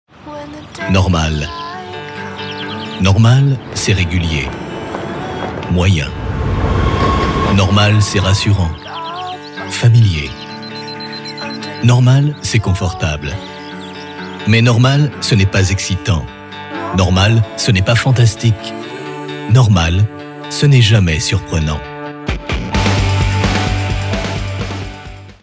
voix off française , voix off publicité , voix off télé , voix off e learning , voix off mooc , voix off radio , voix off télé
Sprechprobe: Sonstiges (Muttersprache):